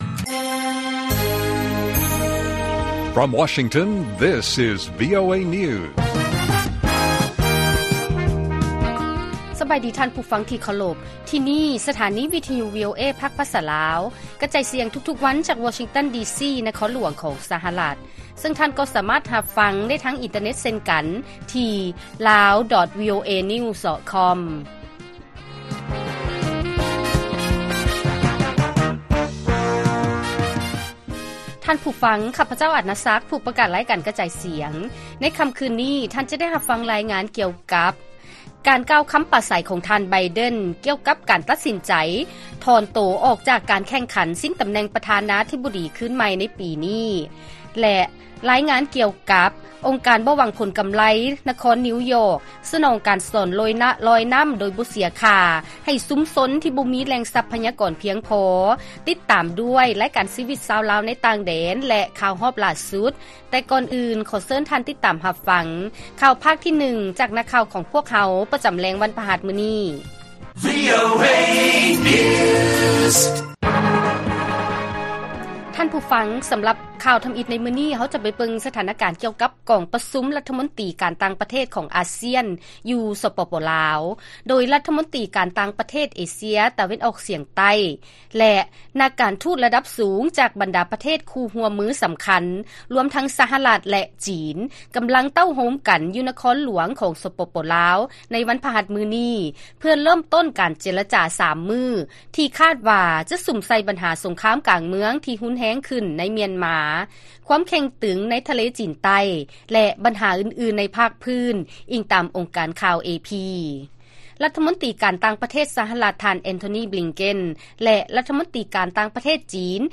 ລາຍການກະຈາຍສຽງຂອງວີໂອເອລາວ: ຄວາມຮຸນແຮງໃນມຽນມາ ແລະຄວາມເຄັ່ງຕຶງໃນທະເລຈີນໃຕ້ ແມ່ນບັນຫາສຳຄັນອັນດັບຕົ້ນໆ ຢູ່ທີ່ກອງປະຊຸມ ASEAN ໃນ ສປປ ລາວ